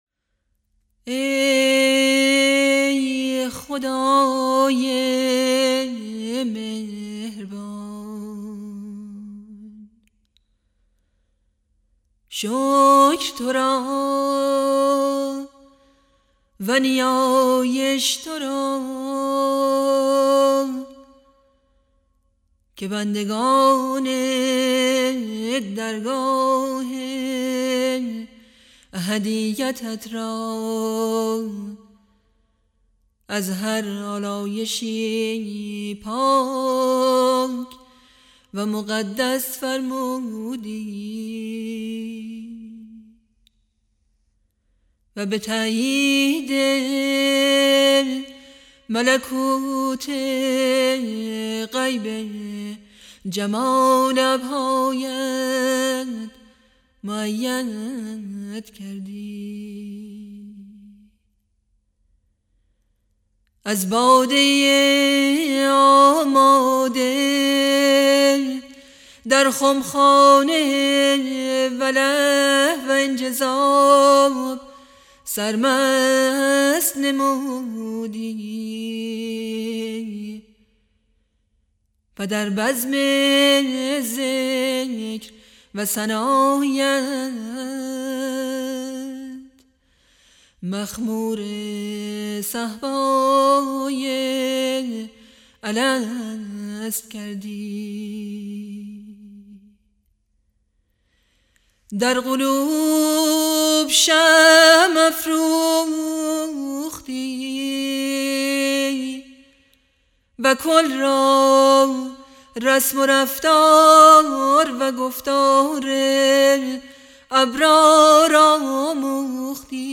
مناجات های صوتی فارسی